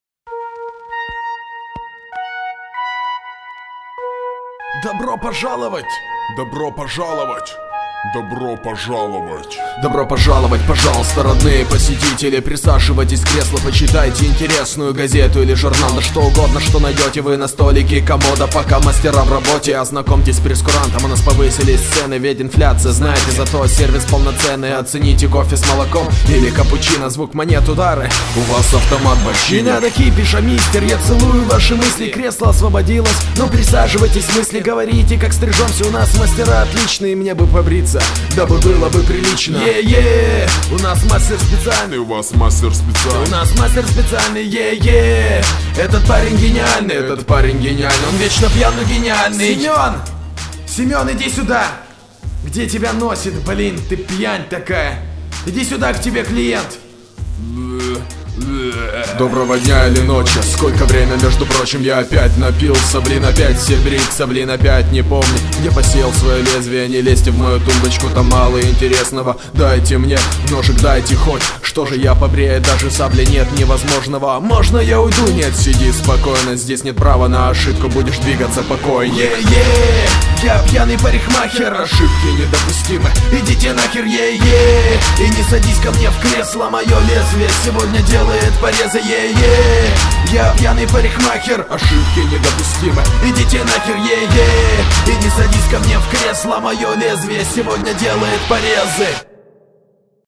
• Жанр: Арт-рок